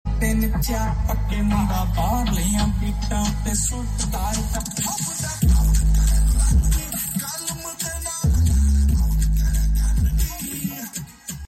Sp2 Speakers Bass Boosted!